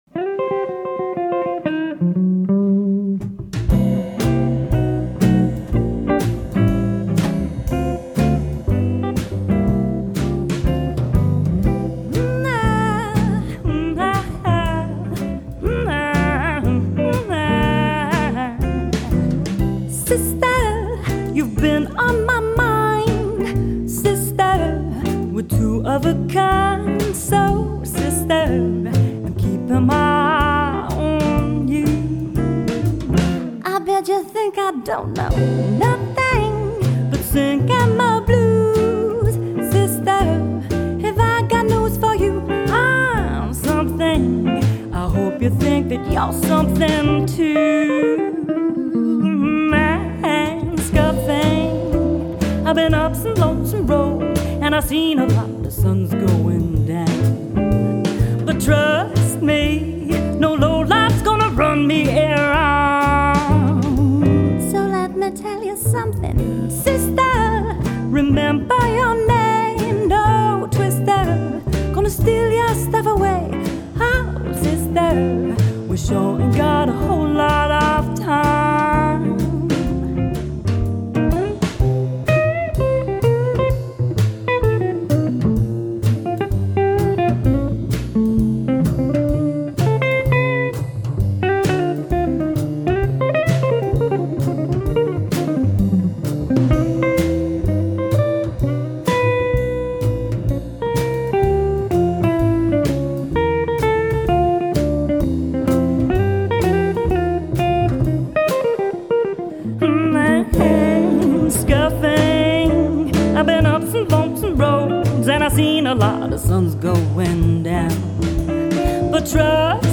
guitar phrasing
double bass
vocals